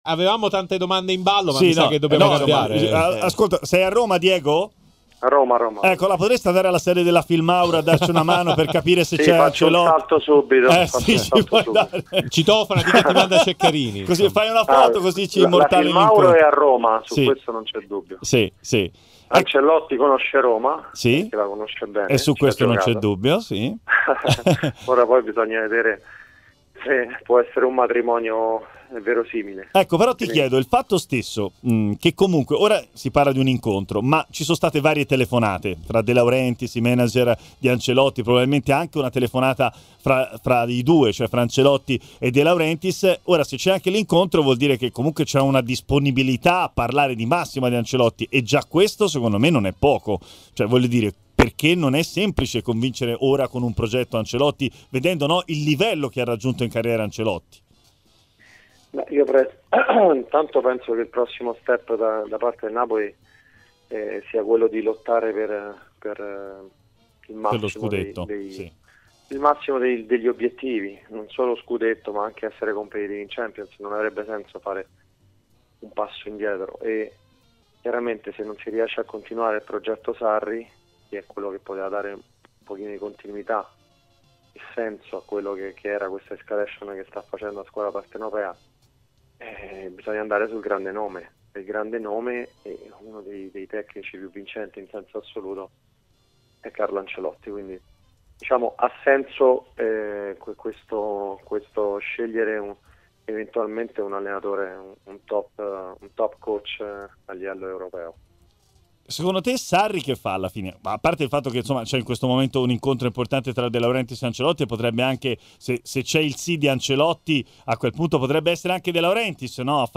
su ipotesi Ancelotti al Napoli, su futuro Sarri. In studio